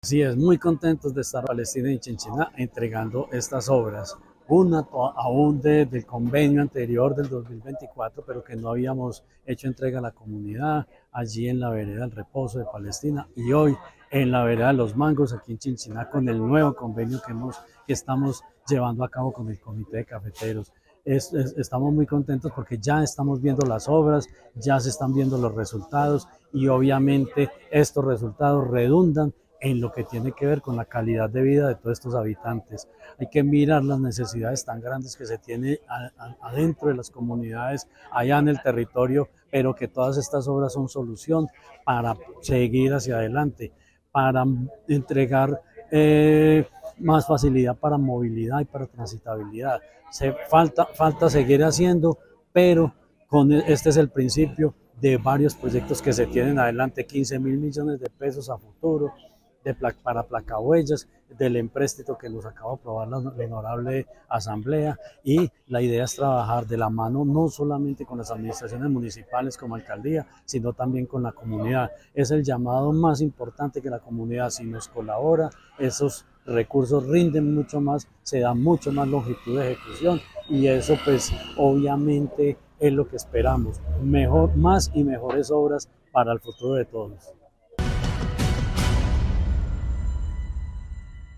Jorge Ricardo Gutiérrez Cardona, secretario de Infraestructura